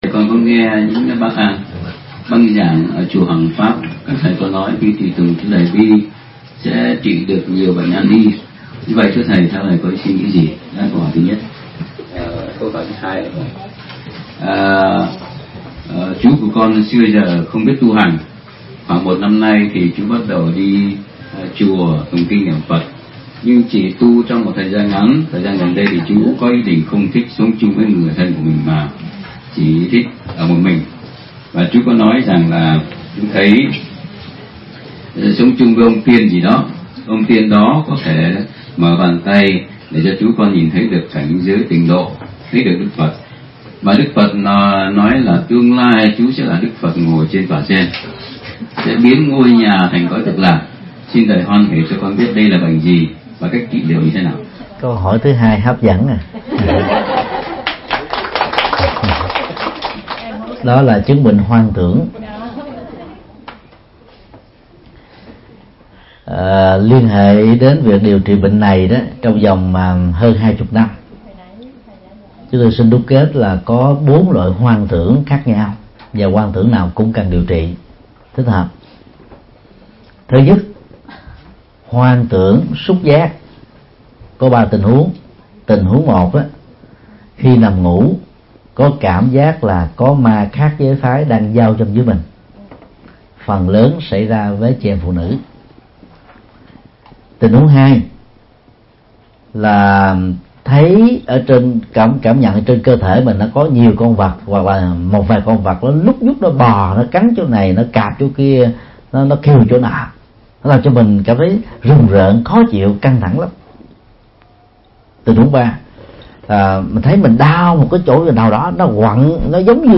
Vấn đáp: Trị bệnh tâm thần hoang tưởng